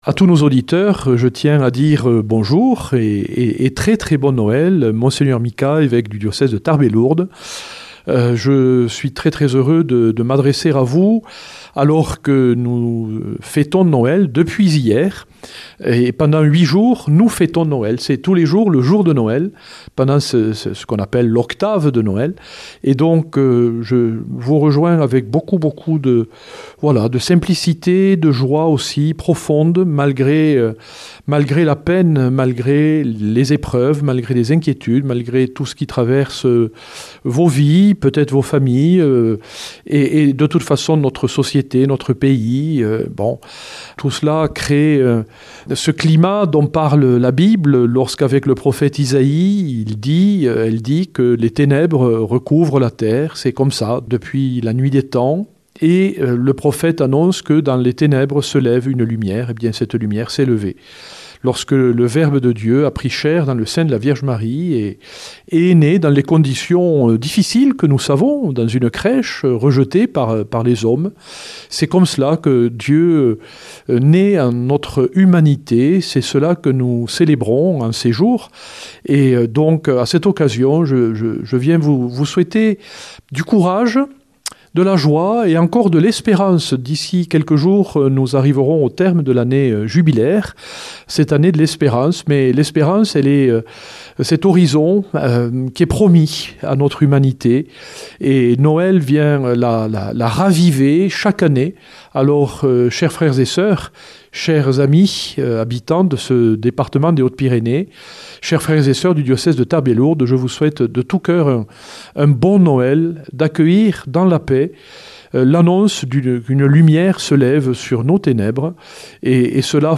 vendredi 26 décembre 2025 Entretien avec Mgr Micas - Évêque de Tarbes Lourdes Durée 12 min